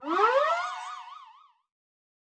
Media:Medic_evo2_dep.wav 部署音效 dep 在角色详情页面点击初级、经典、高手和顶尖形态选项卡触发的音效